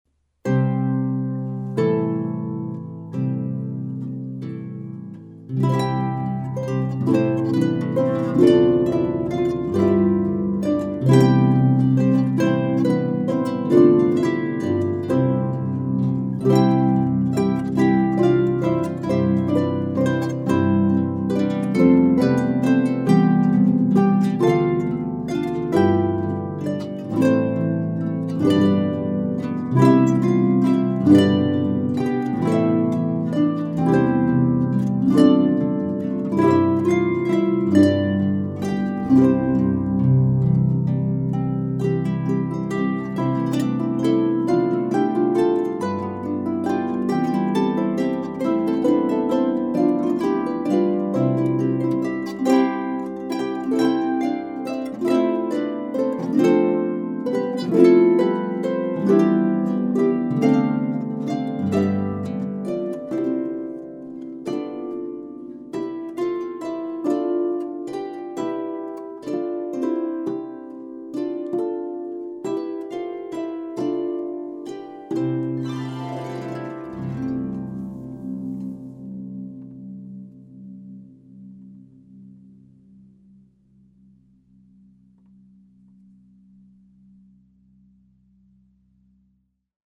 Christmas arrangements